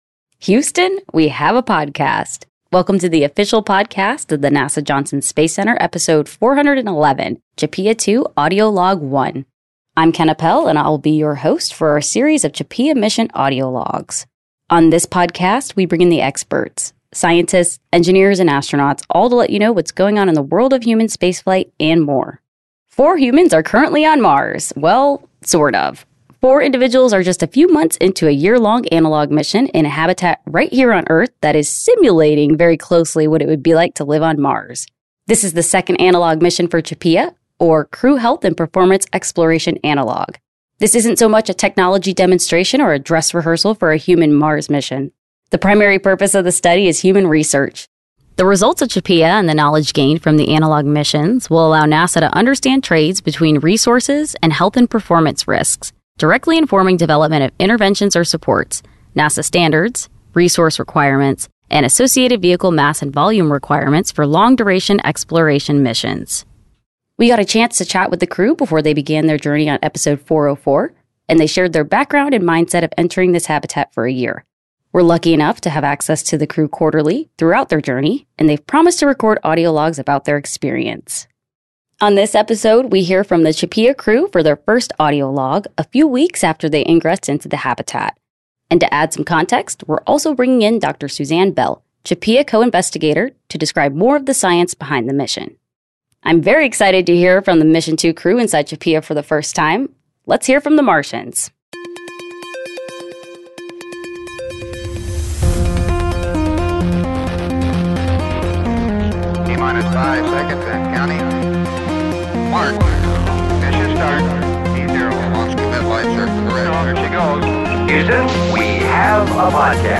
We're lucky enough to have access to the crew quarterly throughout their journey, and they've promised to record audio logs about their experience.